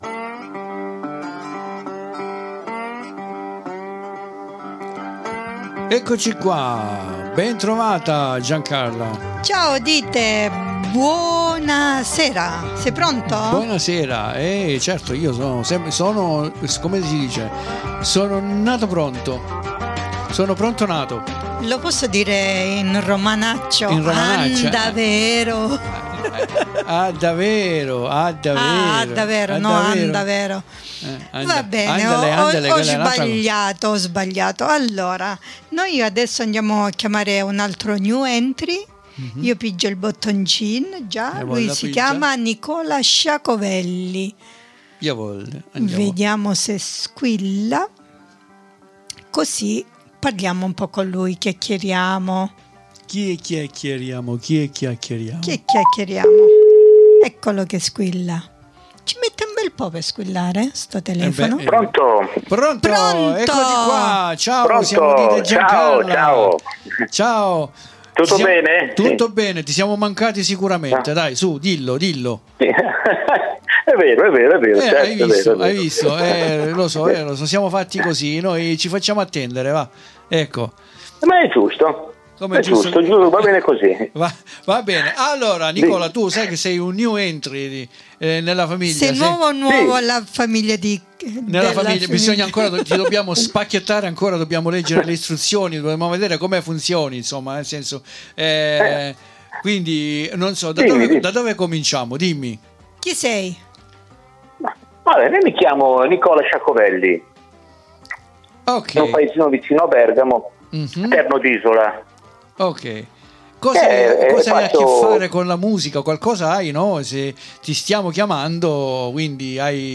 IL RESTO LO ASCOLTERETE DALL'INTERVISTA CONDIVISA QUI IN DESCRIZIONE!